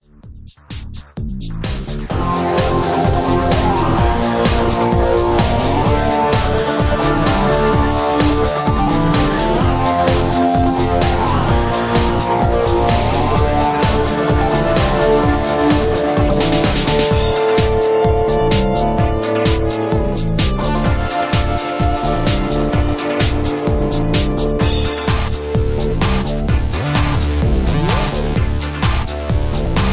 (Key-Dm) Karaoke MP3 Backing Tracks
Just Plain & Simply "GREAT MUSIC" (No Lyrics).